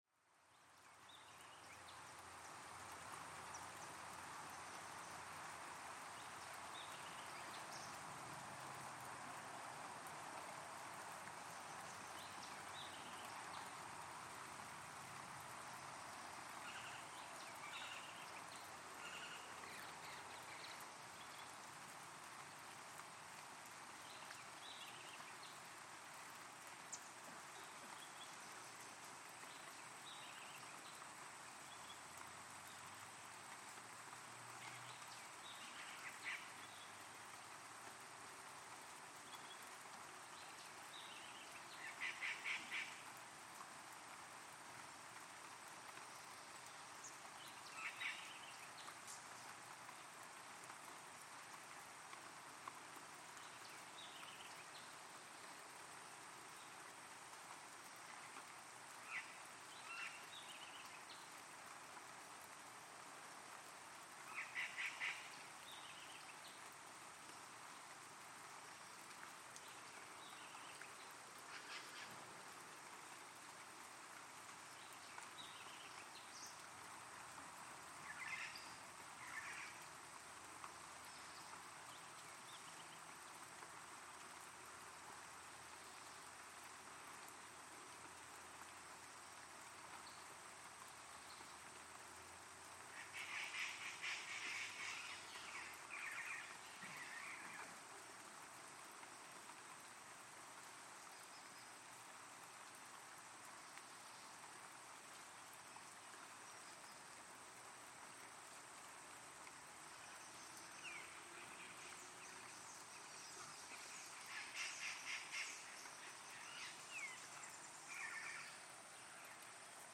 Morning rain in Palenque jungle
Stereo 48kHz 24bit.